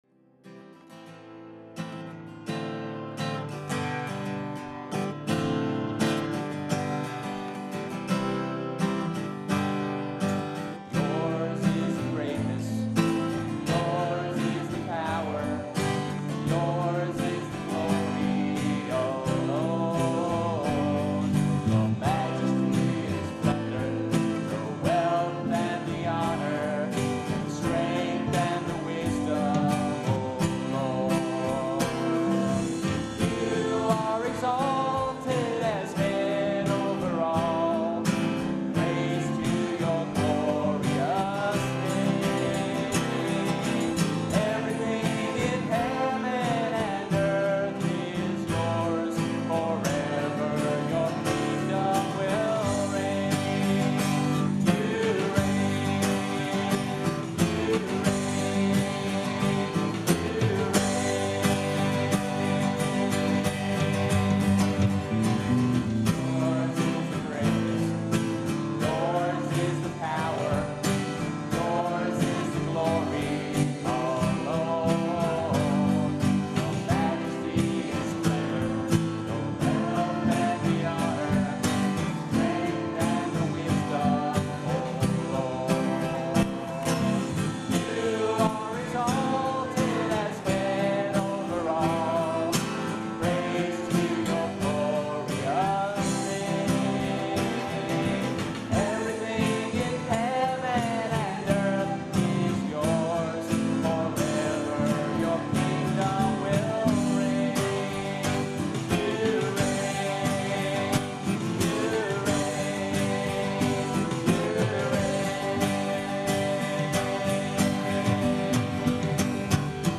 a song of joyful exaltation